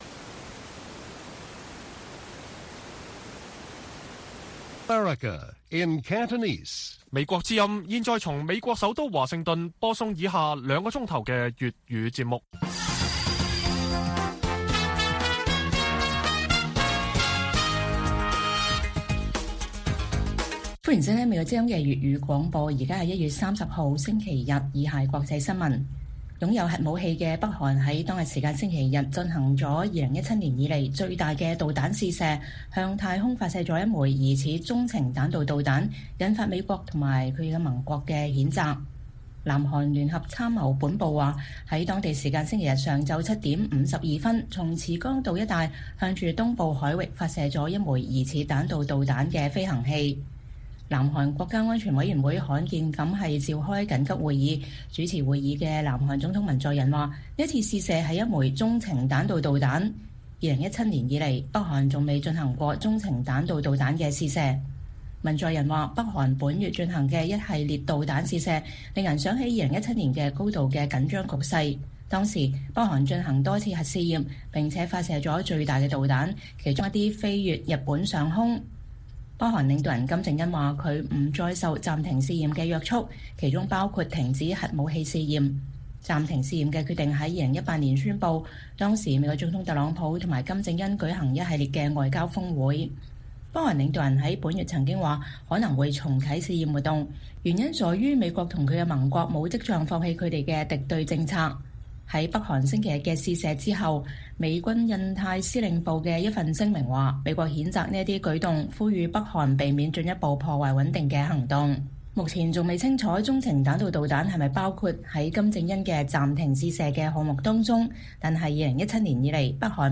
粵語新聞 晚上9-10點：北韓進行了2017年以來最遠射程導彈試驗